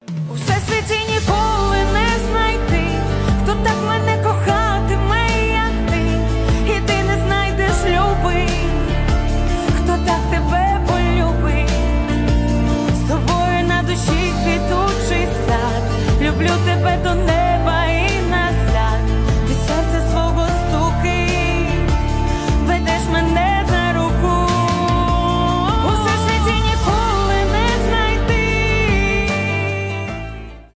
романтические
поп